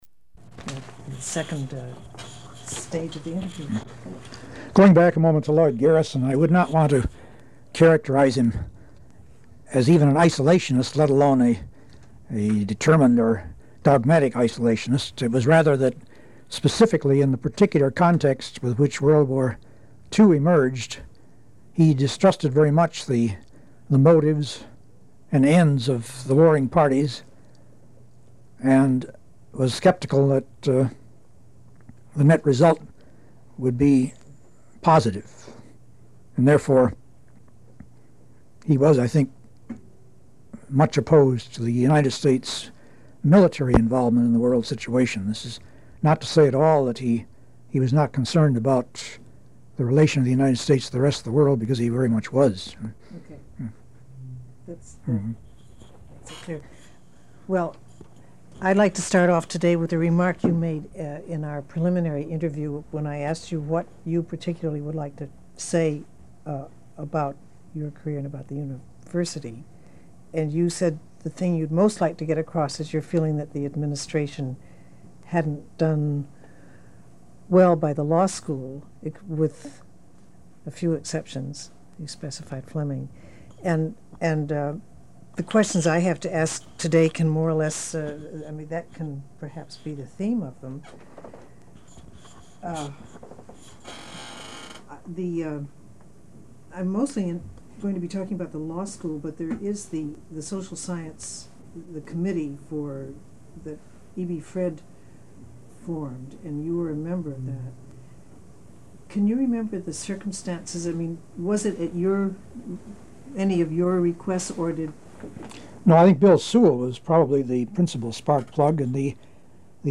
Oral History Interview: Willard Hurst (197)